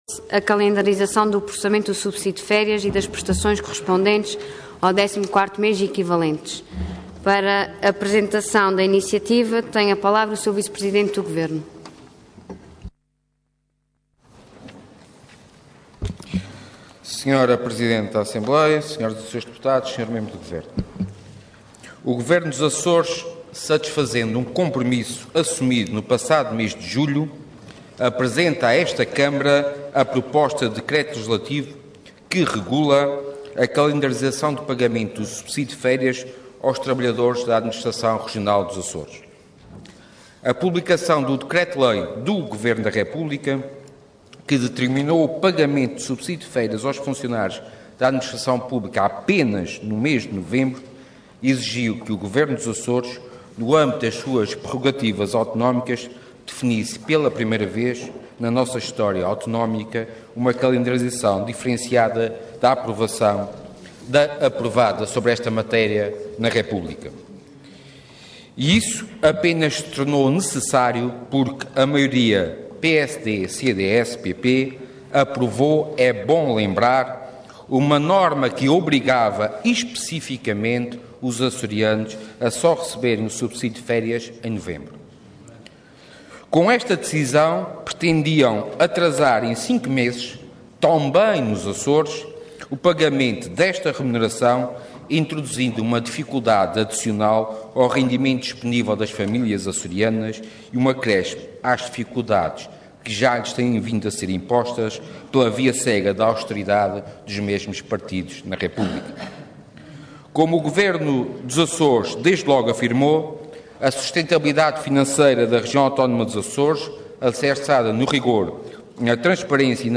Orador Sérgio Ávila Cargo Vice-Presidente do Governo Regional Entidade Governo